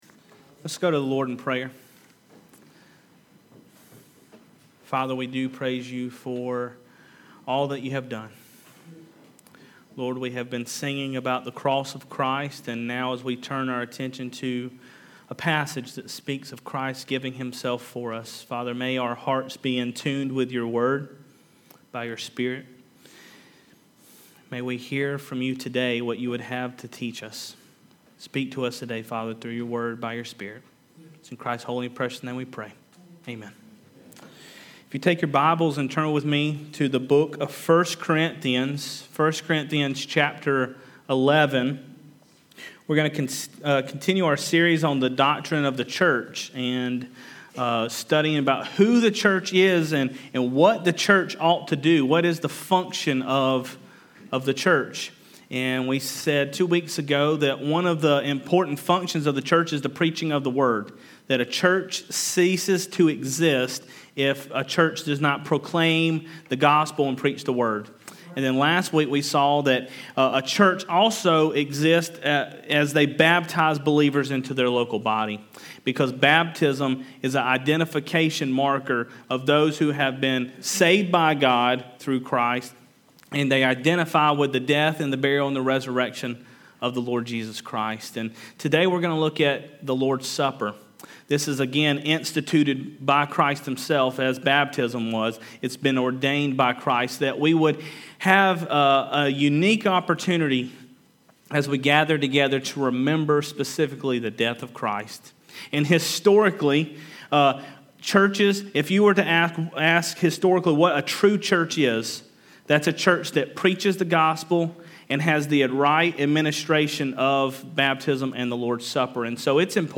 Sunday Morning Worship